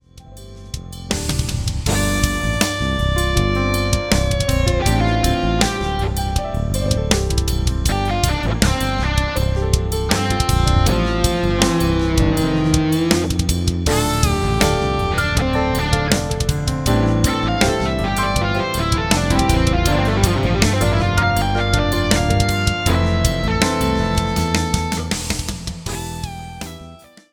raw - mix - master
raw
aruna-RAW.wav